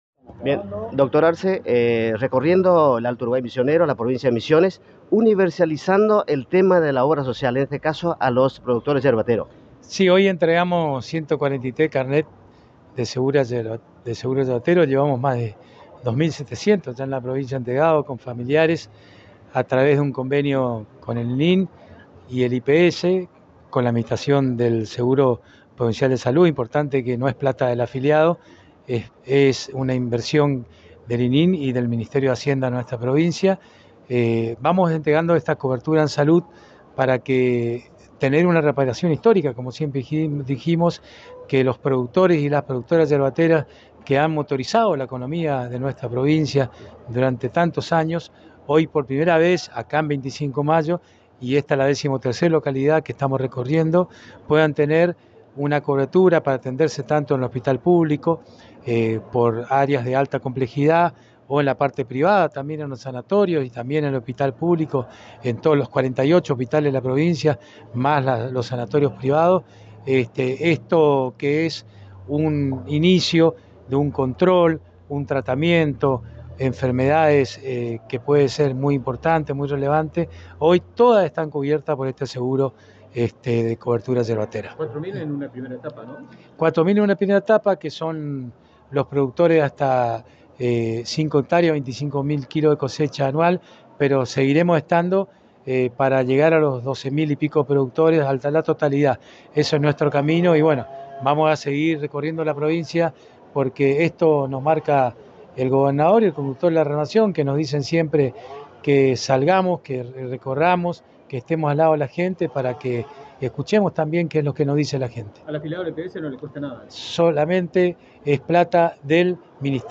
Audio: Vicegobernador Carlos Arce